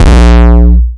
death kick.wav